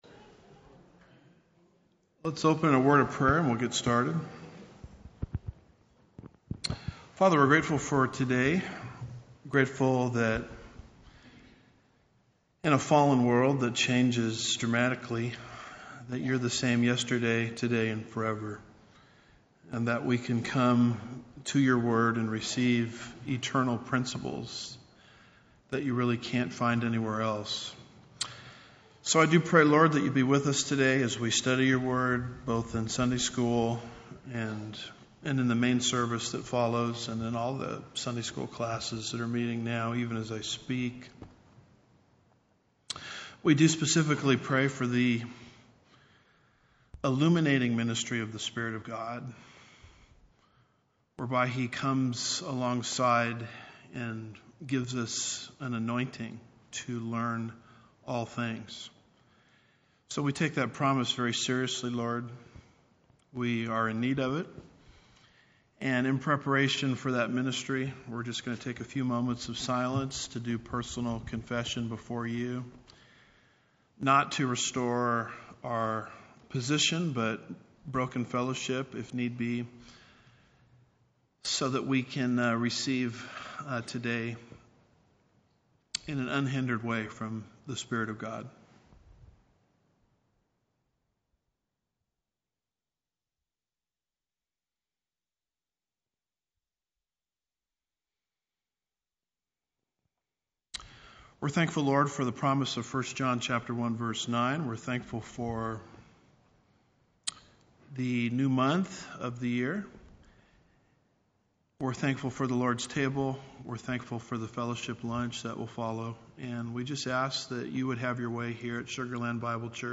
Second Thessalonians 032 – Tradition? Home / Sermons / Second Thessalonians 032 - Tradition?